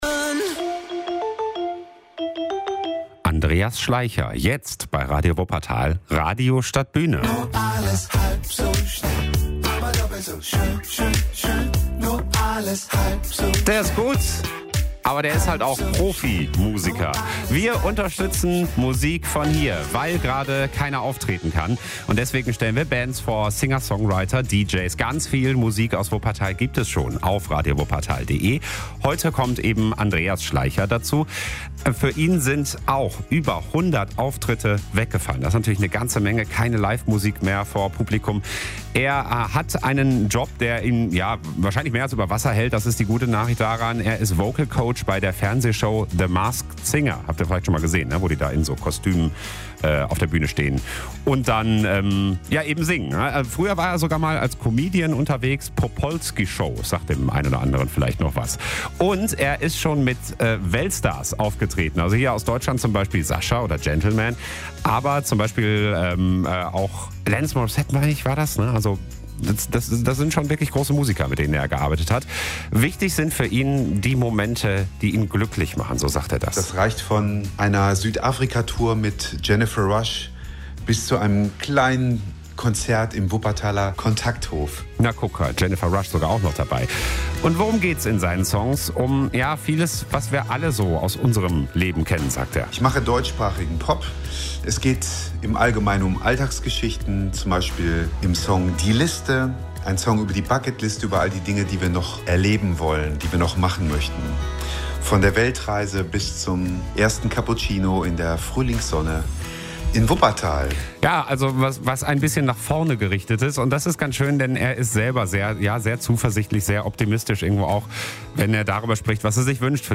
Deutschsprachige Popmusik.